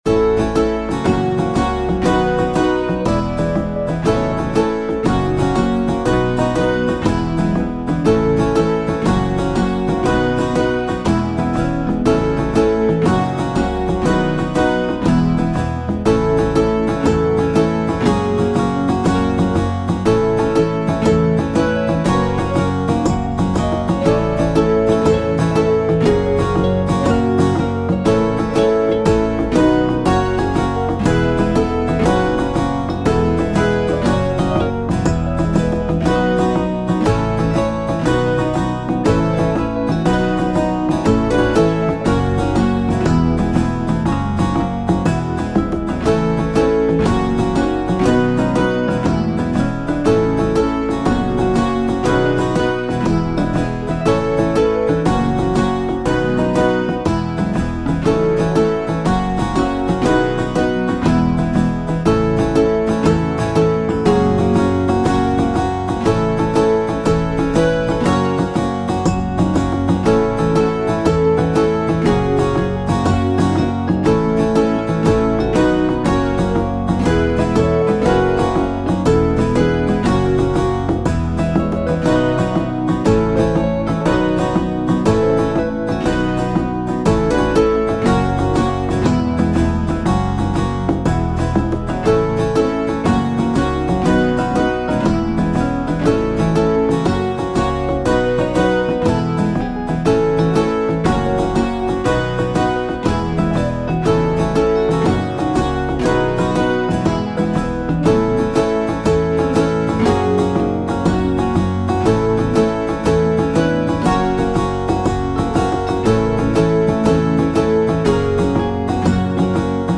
midi-demo 1